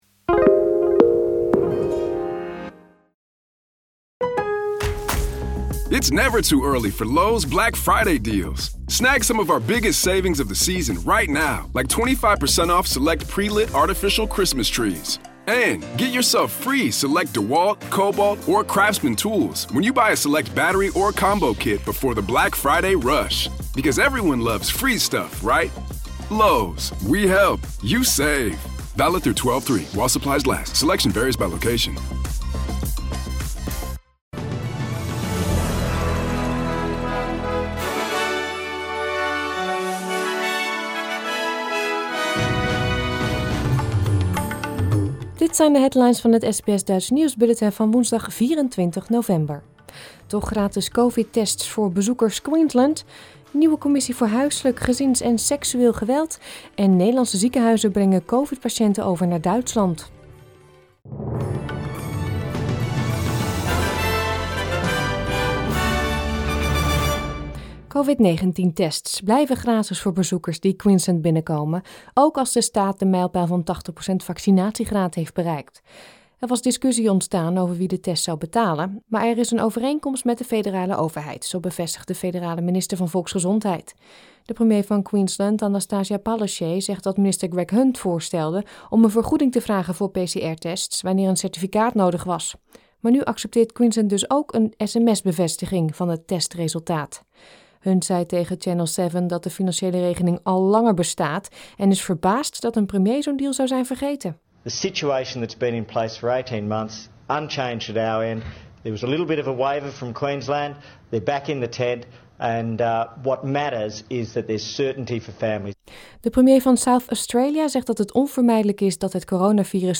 Nederlands / Australisch SBS Dutch nieuwsbulletin van woensdag 24 november 2021